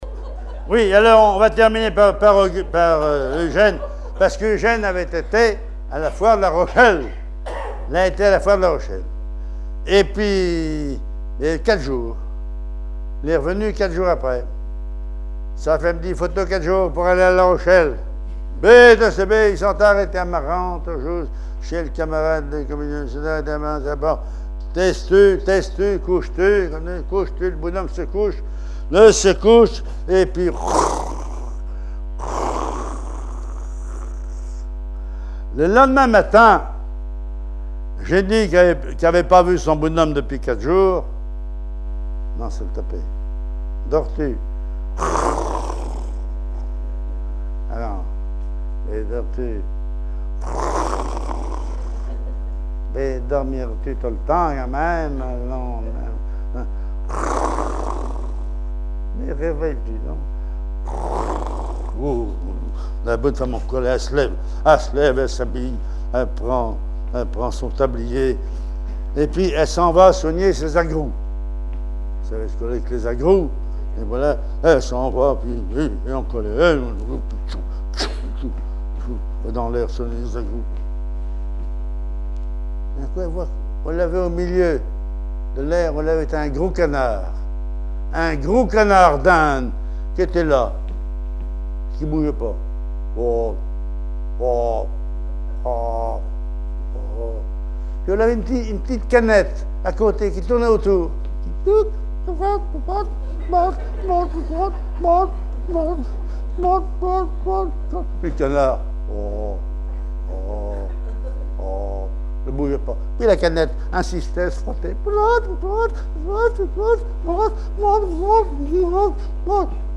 Langue Patois local
Genre sketch
Catégorie Récit